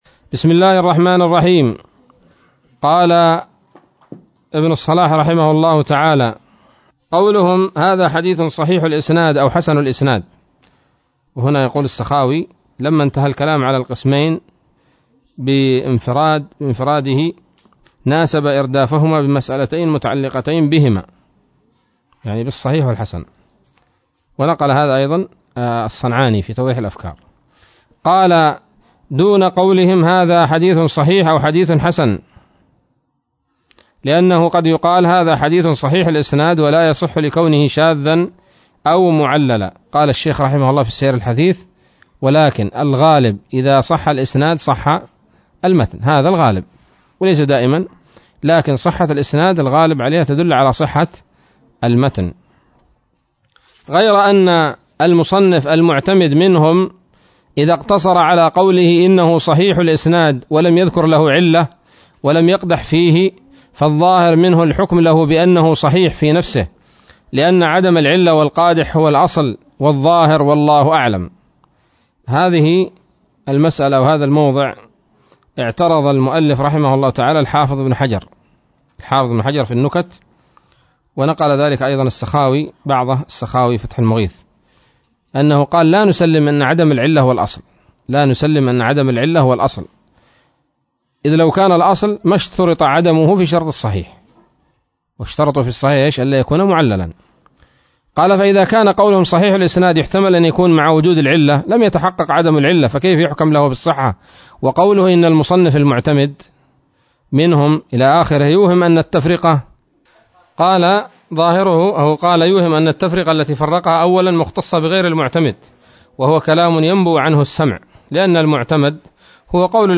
الدرس السابع عشر من مقدمة ابن الصلاح رحمه الله تعالى